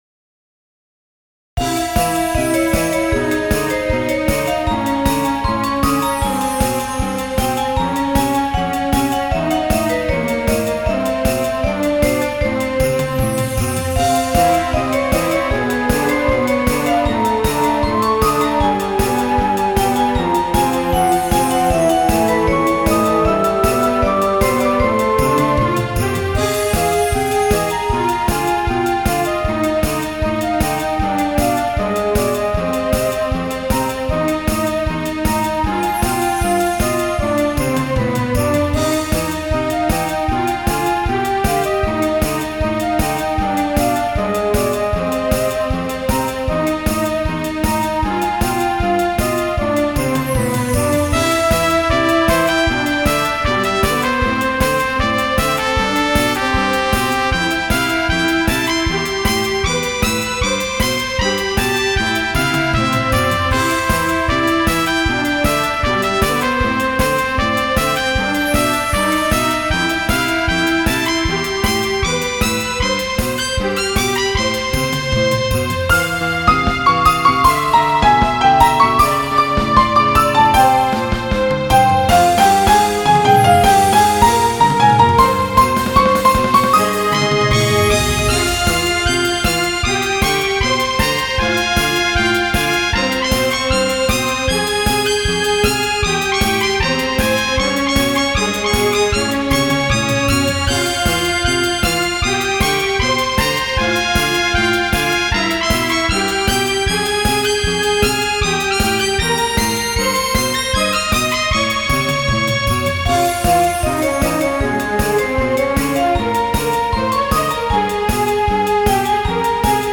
ジャンルPop